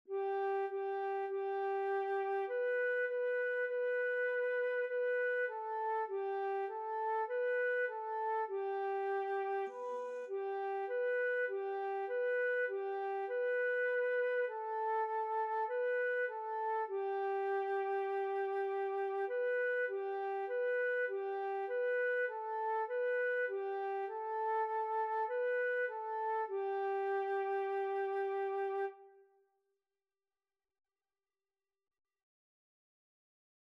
4/4 (View more 4/4 Music)
G5-B5
Flute  (View more Beginners Flute Music)
Classical (View more Classical Flute Music)